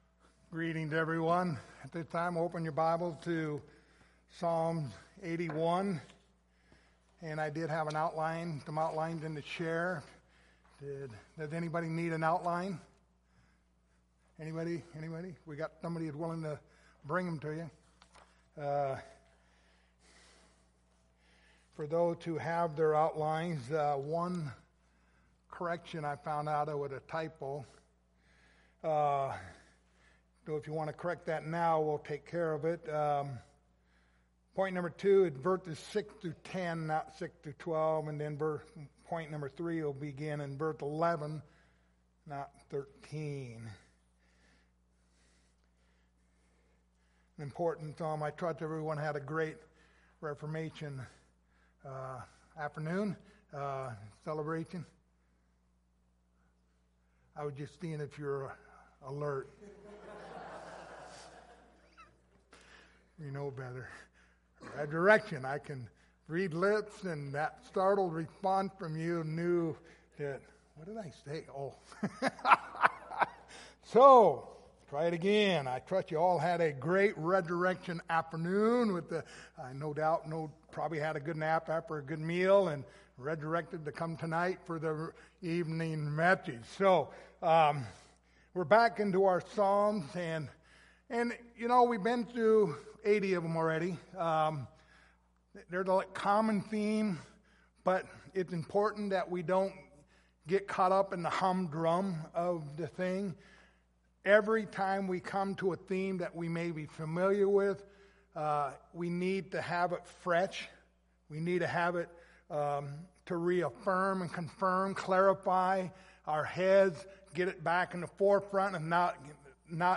The book of Psalms Passage: Psalms 81:1-16 Service Type: Sunday Evening Topics